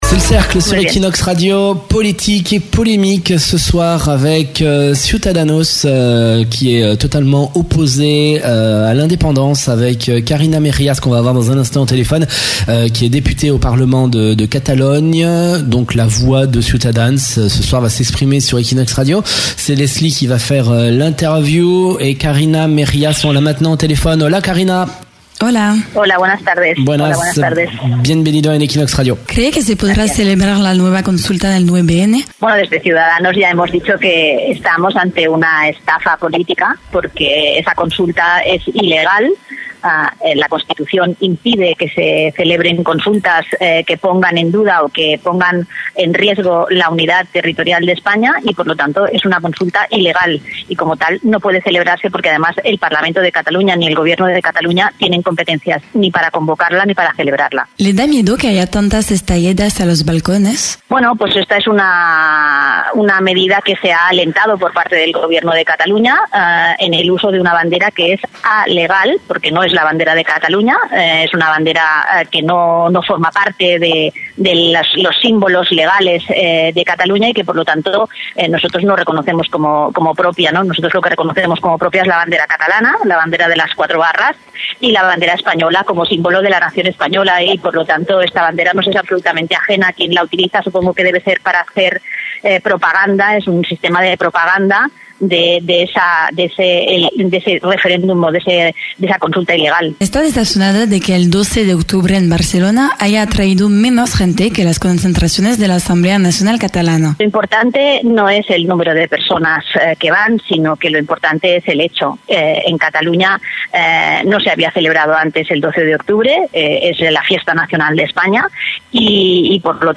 Lors d’une interview sur Equinox Radio Barcelone elle fait le point sur le processus d’indépendance et  la corruption.